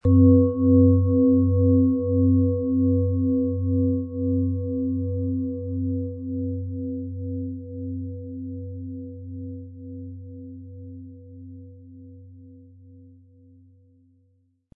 Ihre antike Klangschale – ein Begleiter für Klarheit und innere Wärme
Mit einem tiefen, warmen Klang erfüllt die Schale den Raum und entfaltet besonders im Brustbereich eine sanfte, wohltuende Wärme.
Die Schwingungen sind kraftvoll und intensiv, direkt am Schalenboden zu spüren, und schenken Ihnen das Gefühl, geschützt und geborgen zu sein.
Diese Klangschale schwingt im besonderen Biorhythmus Geist-Planetenton.